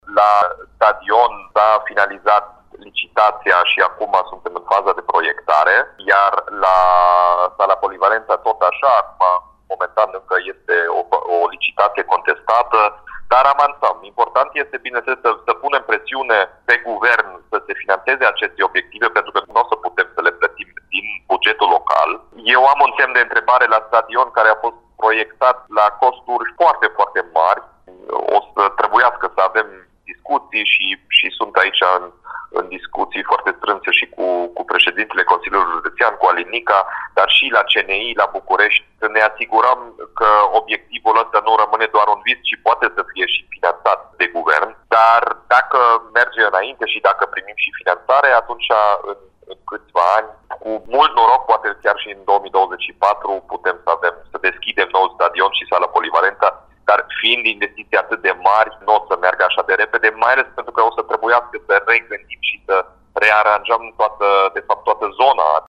„Nu cred că este fezabil ca politicul să facă parte din conducerea acestor cluburi, fie direct sau indirect, pentru că politica nu are ce căuta în sport”, a concluzionat Dominic Fritz, într-un interviu pentru Radio Timișoara.